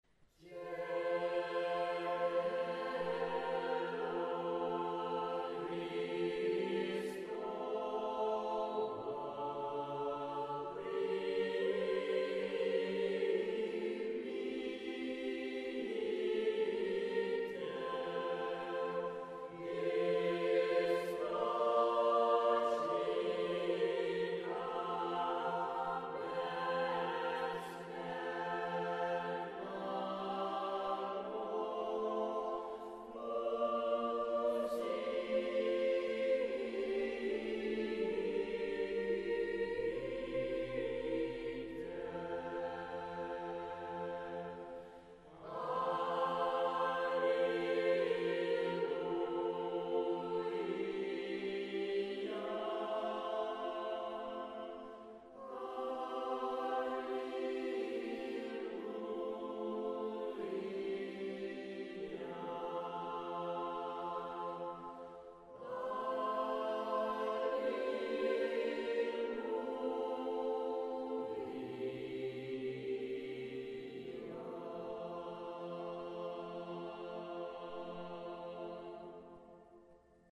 “B” Liturgia – négyszólamú ünnepi dallamokkal (mp3)